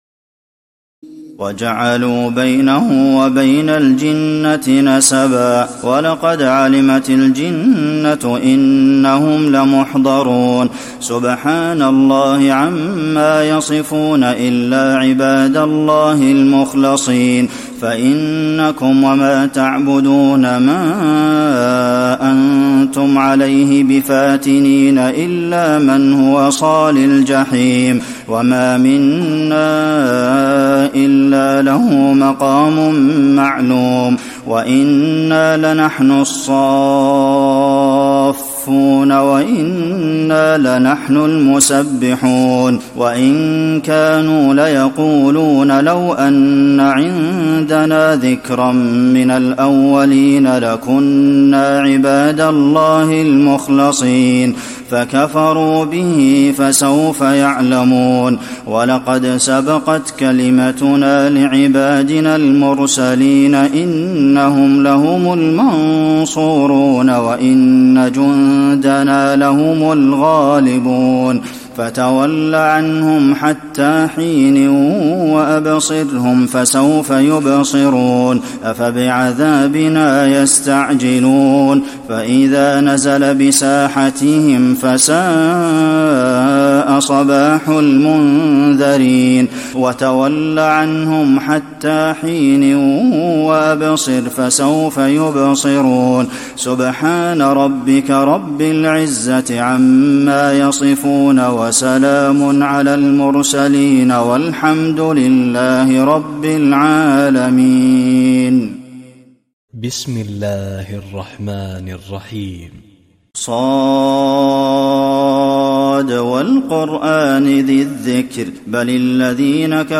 تراويح ليلة 22 رمضان 1435هـ من سور الصافات (158-182) وص و الزمر (1-31) Taraweeh 22 st night Ramadan 1435H from Surah As-Saaffaat and Saad and Az-Zumar > تراويح الحرم النبوي عام 1435 🕌 > التراويح - تلاوات الحرمين